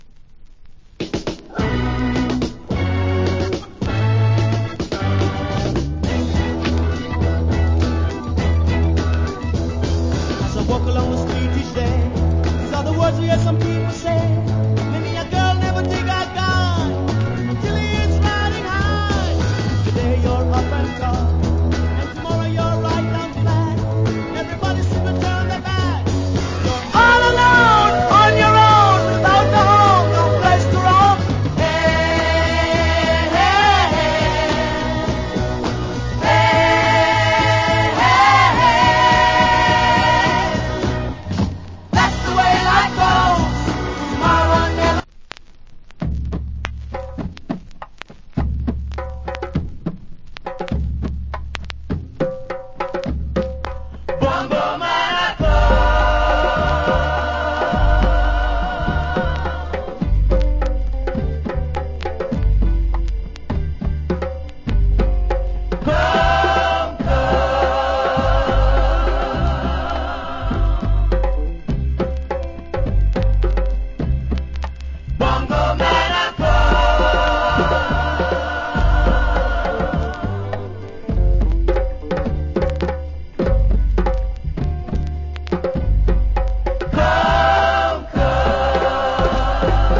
Cool Vocal.